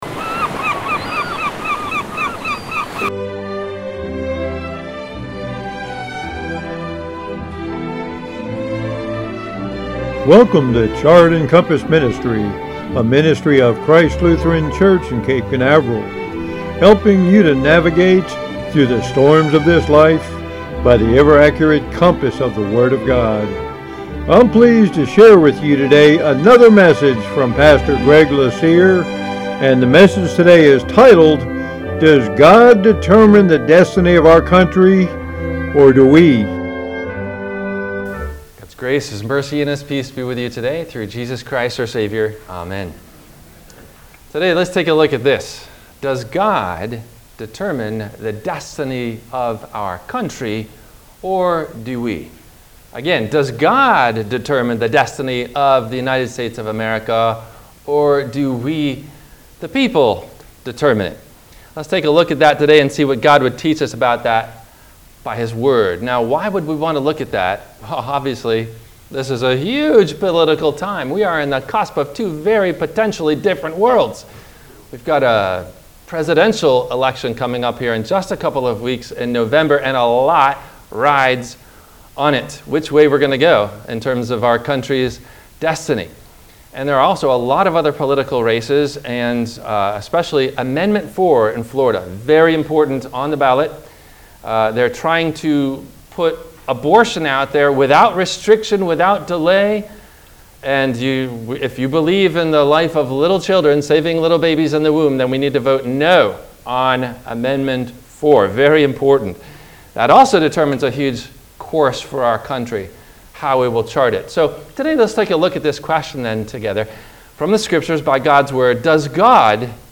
Does God Determine the Destiny of our Country … or do We? – WMIE Radio Sermon – October 28 2024
No Questions asked before the Radio Message.